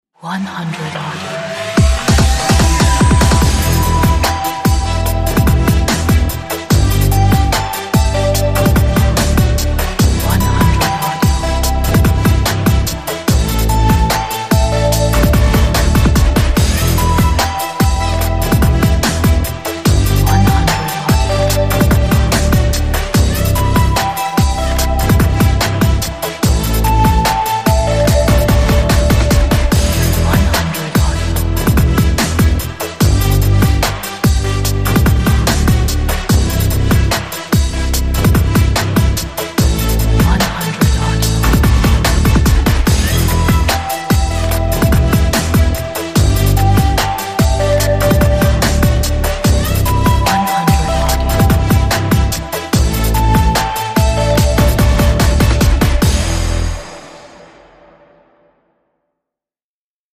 Powerful Hip-Hop, Trap track With a punchy beat and bass.